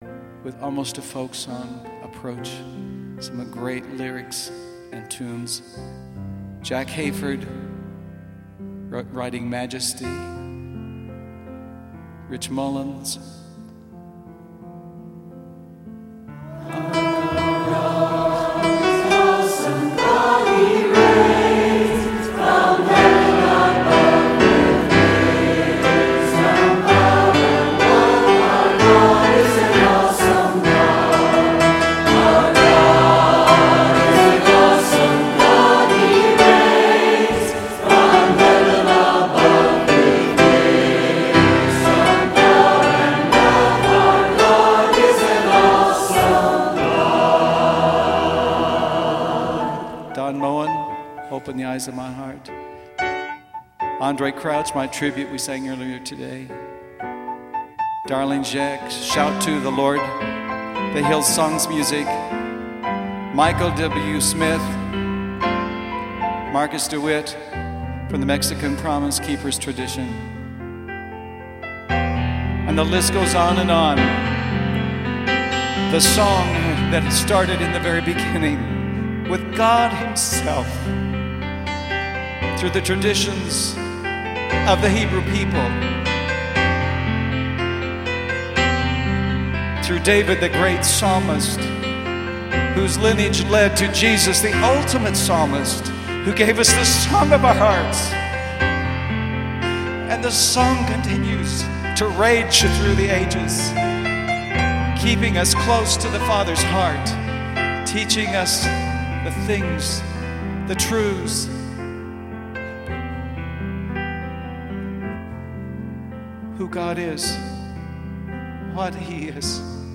Recorded at the Renovaré International Conference in Denver, CO.